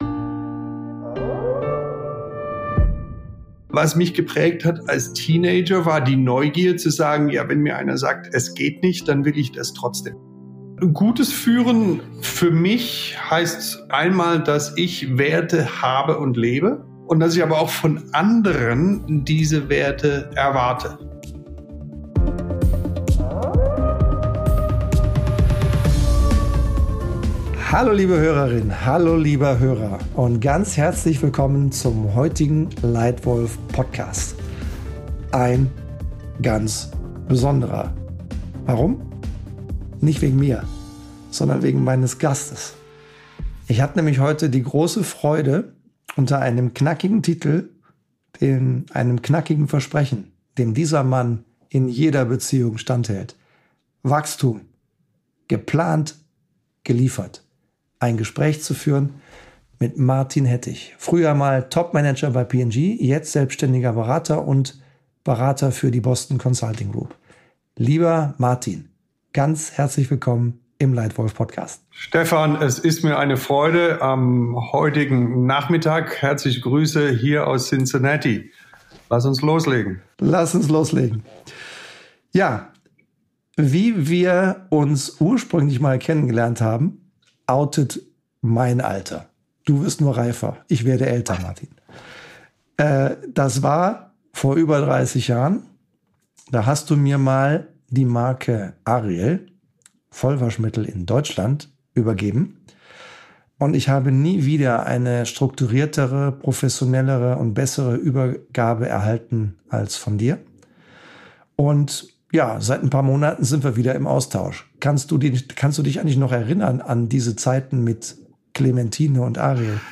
Im Gespräch geht es um unternehmerische Neugier, kritisches Denken und die Fähigkeit, sich selbst immer wieder neu zu erfinden.
Ein zentrales Thema: die Kunst, Nein zu sagen und Zeit dort zu investieren, wo sie echte Wirkung entfaltet. Ein Gespräch über Wachstum mit Substanz, über Führung mit Haltung und über den Mut, sich selbst kontinuierlich weiterzuentwickeln – damit aus Strategie echte Ergebnisse werden.